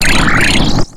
Cri de Clic dans Pokémon X et Y.